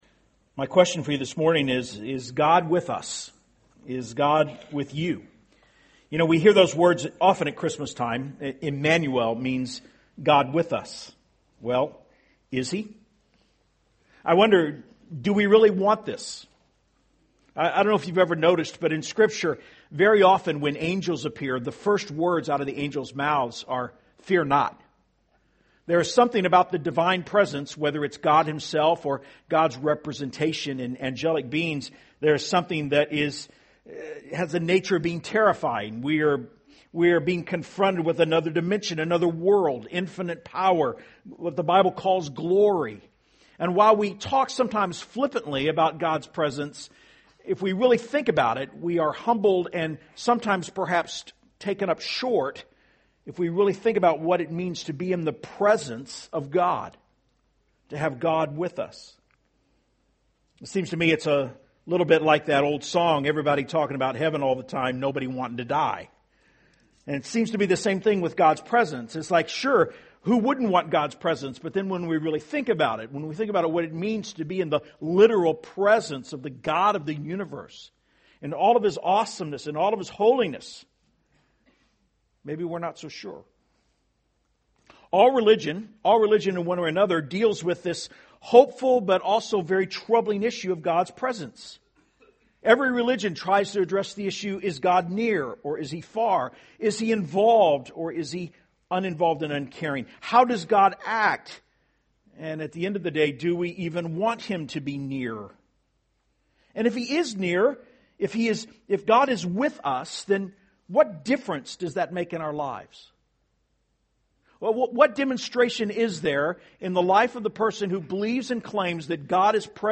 Standalone Sermons Archives - Page 4 of 5 - C3 Houston